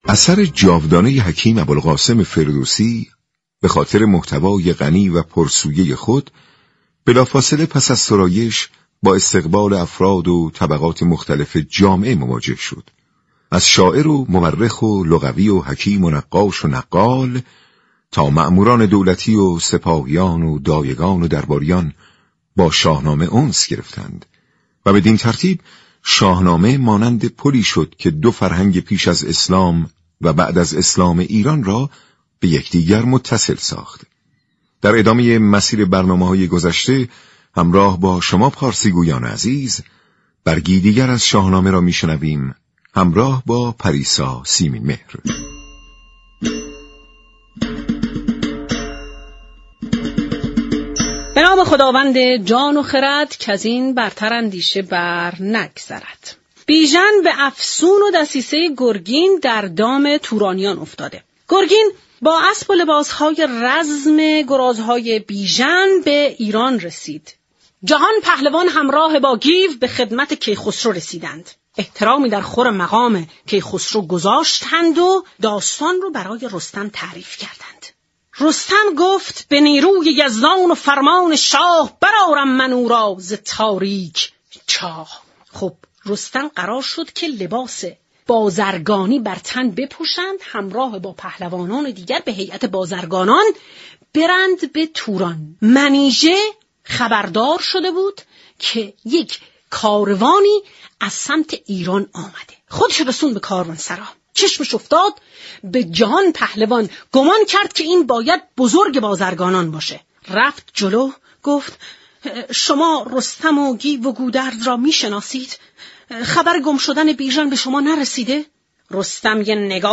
برنامه پارسی رادیو ایران به مناسبت این روز، صحبت های برخی بزرگان را در قالب یك گزارش رادیویی پخش كرده است.